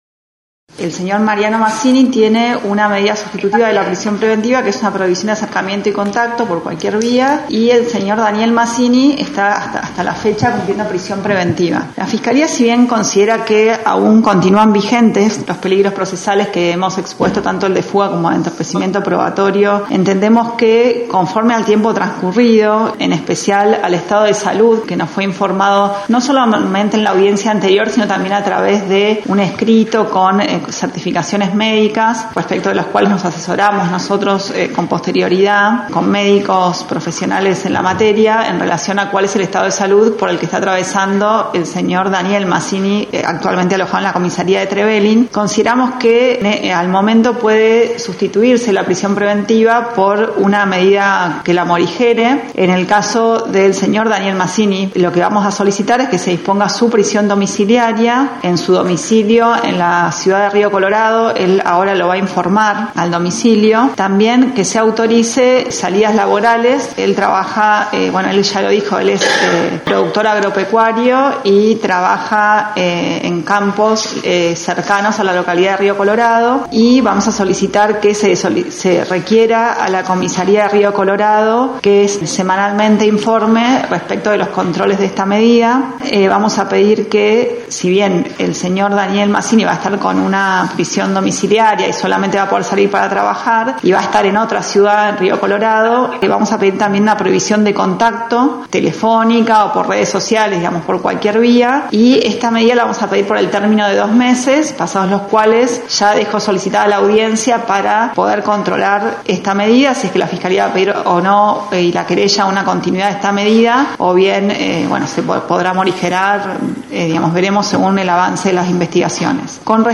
En los tribunales de Esquel, se realizó la audiencia en la que Noticias de Esquel fue el único medio presente